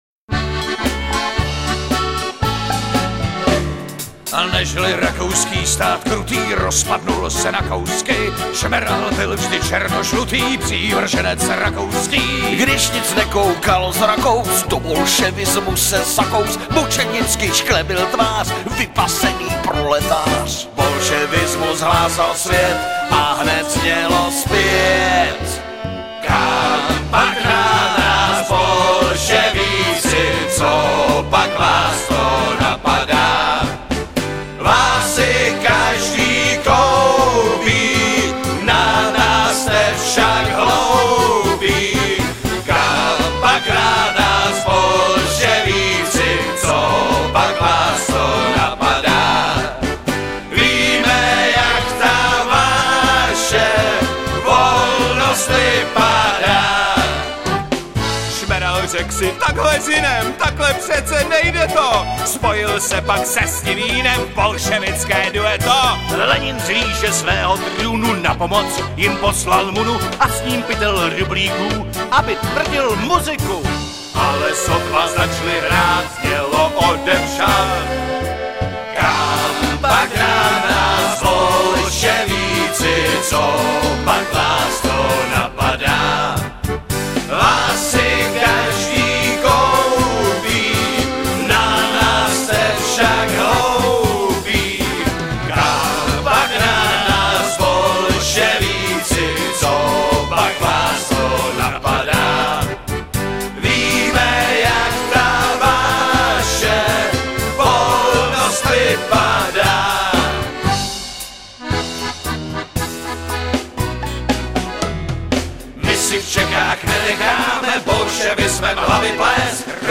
hrají a zpívají
Bum Bar Session